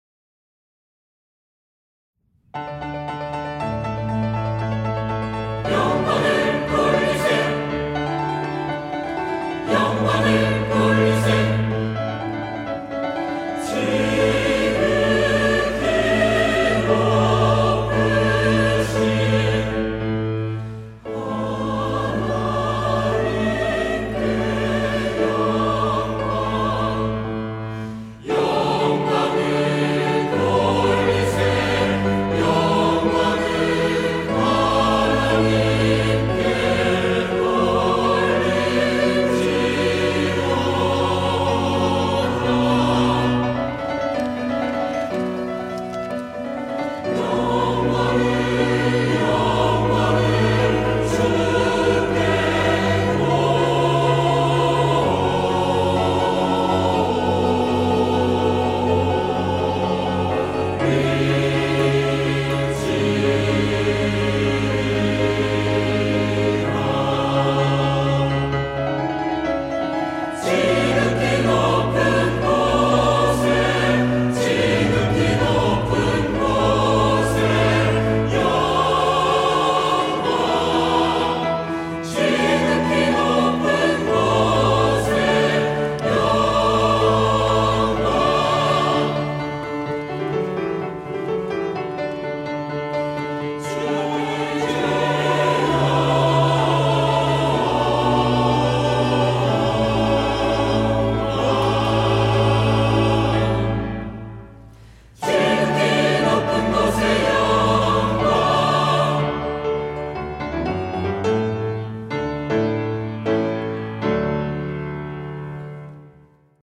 할렐루야(주일2부) - 영광을 돌리세
찬양대